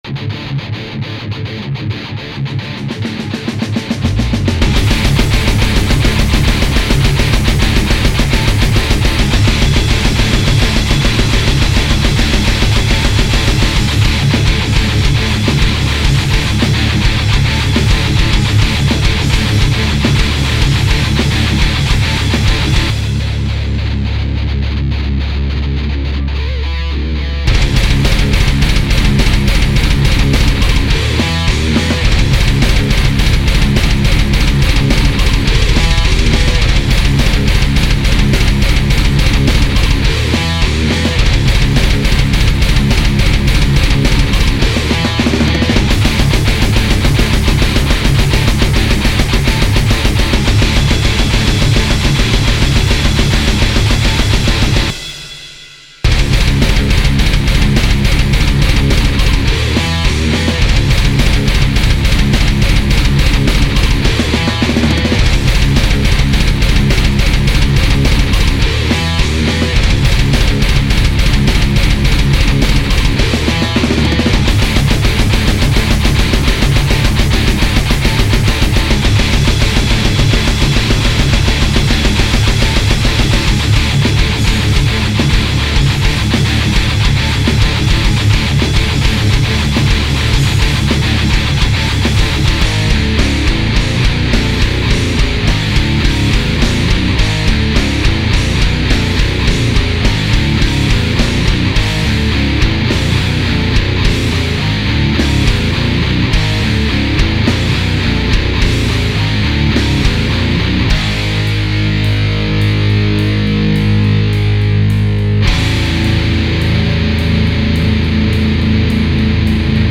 Vos Compos Metal
(La version avec un gros mastering bourrin qui enleve tout la finesse de mon mix :mdr: )
Bien lourd, du vtm de printemps en fait.